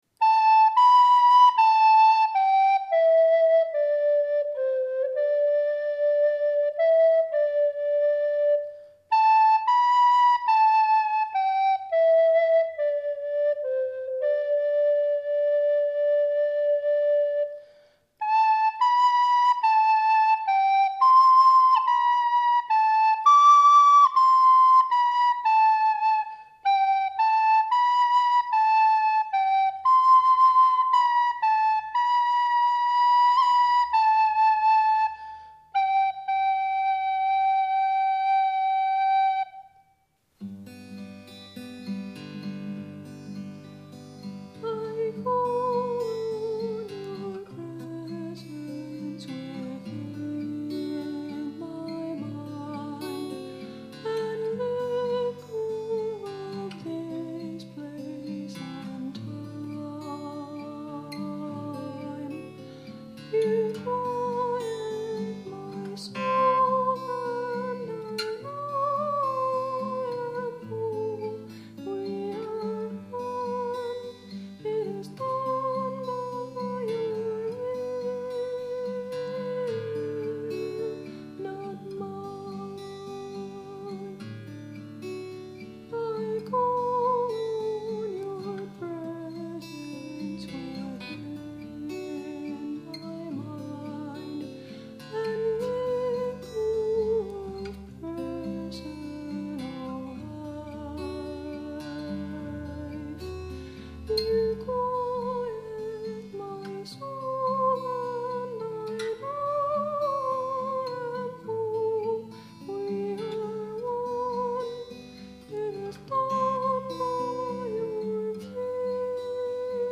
Instruments: Soprano recorder, Tempo – Seagull Excursion Folk Acoustic Guitar